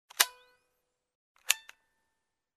Звуки выключателя